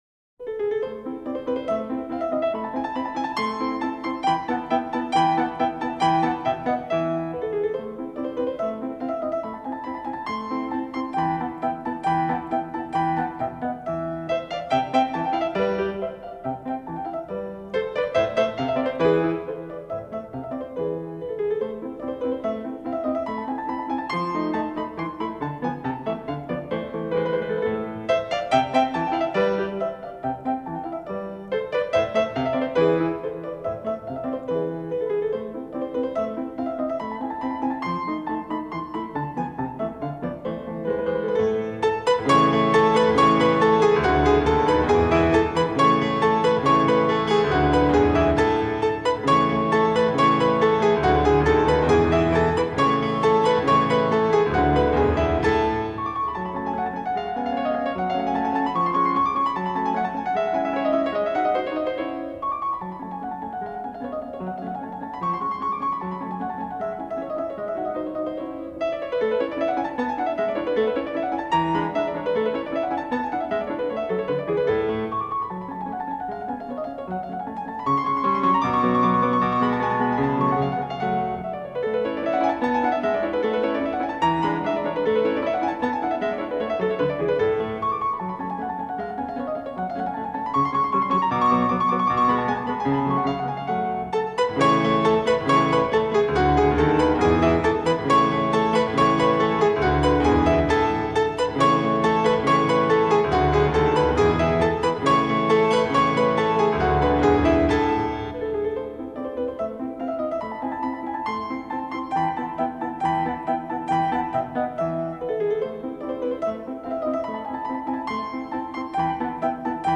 这是一张非常棒的录音
录音尽显高级LP独有的迷人特质